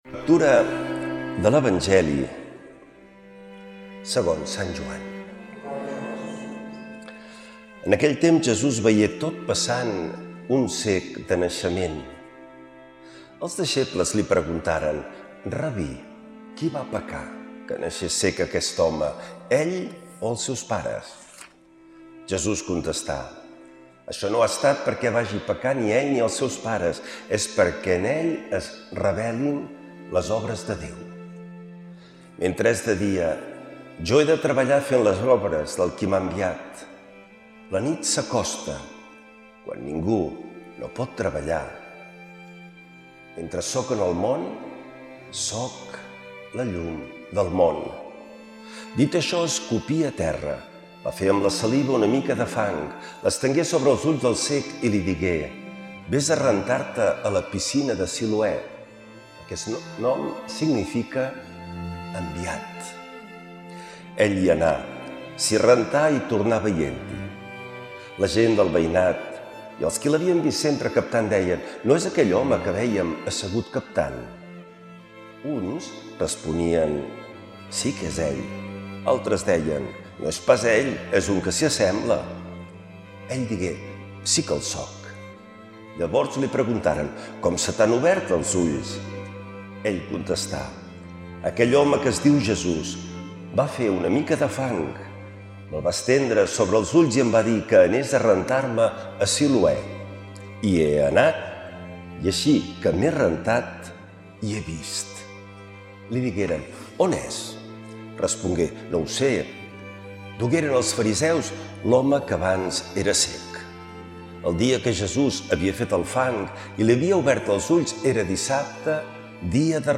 L’Evangeli i el comentari de diumenge 15 de març del 2026.